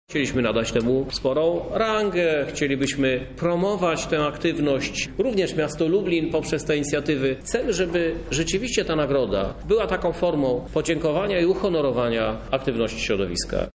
O nagrodzie mówi Krzysztof Żuk, prezydent Lublina